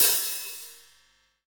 HAT H.H.LO09.wav